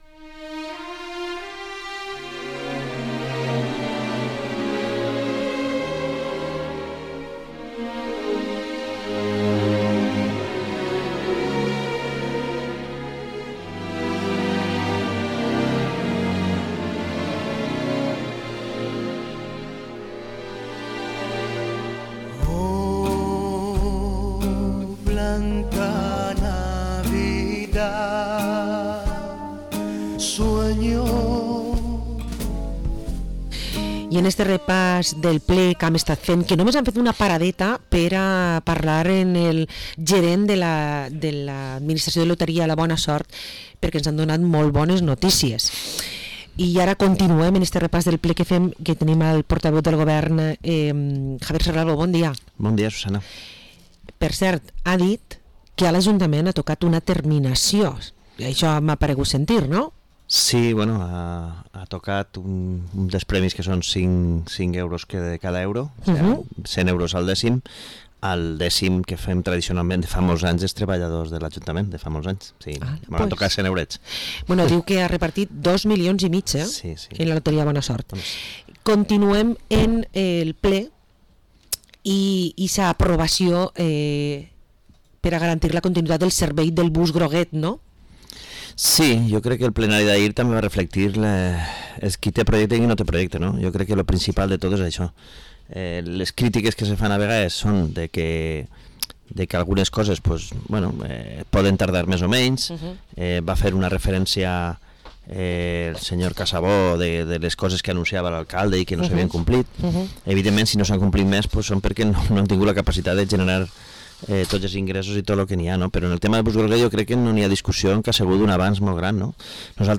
Entrevista al portavoz del equipo de gobierno de Vila-real, Javier Serralvo